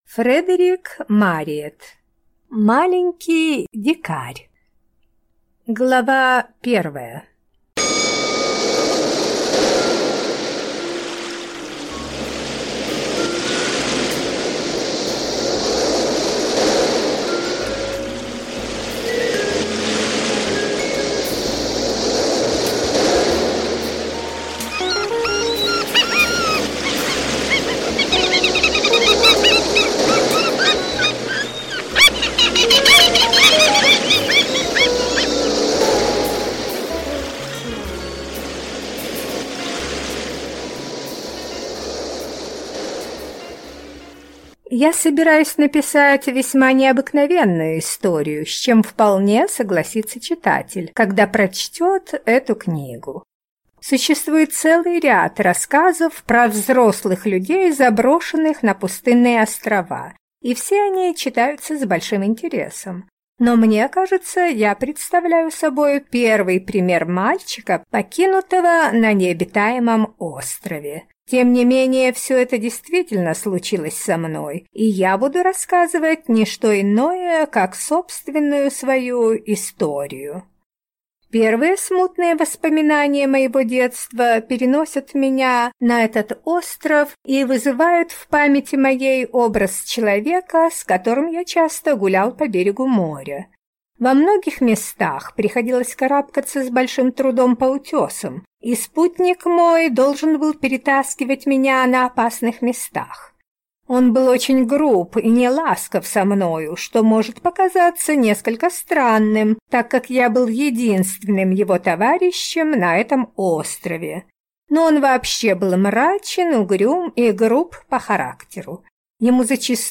Аудиокнига Маленький дикарь | Библиотека аудиокниг